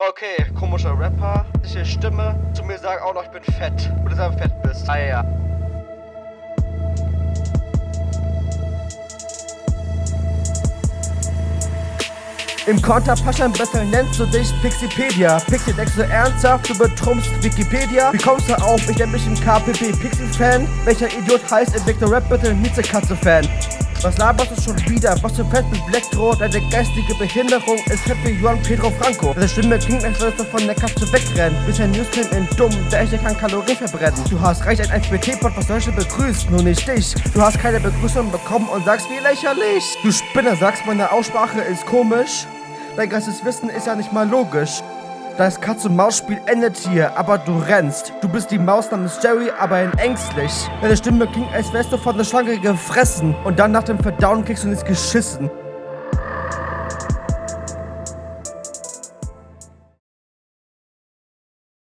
deine Stimme geht bisi mehr auf dem Beat unter
Flow: ist extrems offbeat und überhauptnicht gut gerappt, mach am besten nach 2 lines immer …